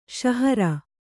♪ śahara